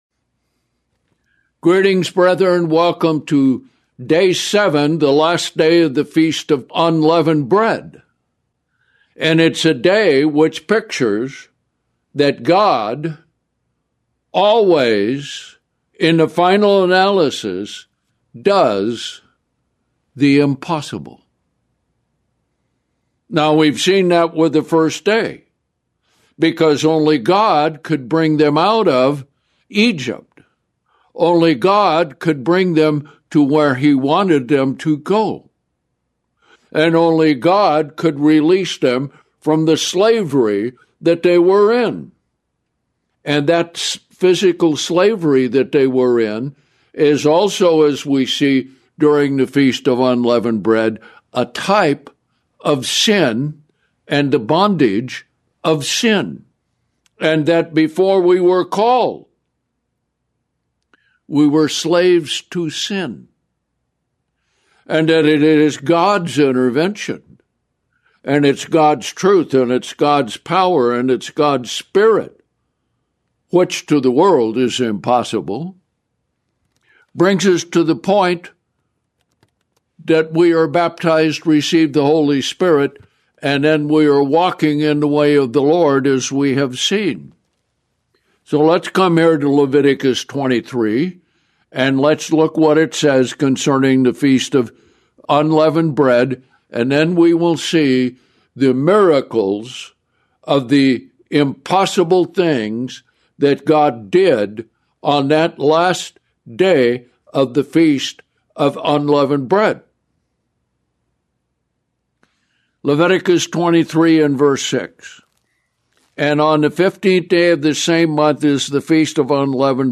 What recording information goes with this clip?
7th-Day-of-Unleavened-Bread-2025.mp3